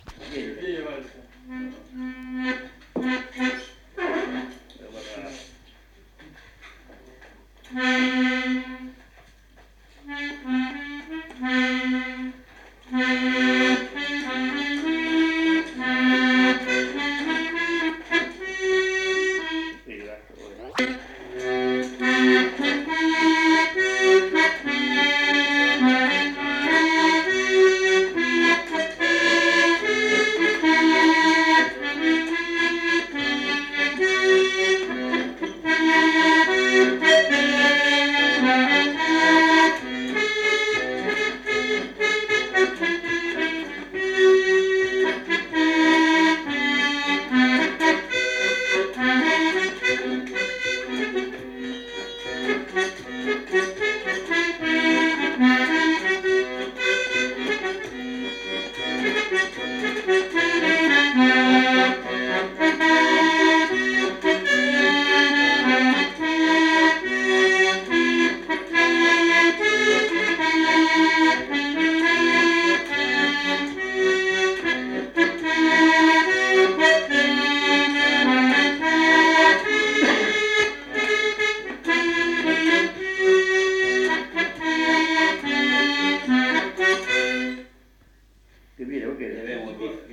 Valse
Aire culturelle : Petites-Landes
Lieu : Lencouacq
Genre : morceau instrumental
Instrument de musique : accordéon diatonique
Danse : valse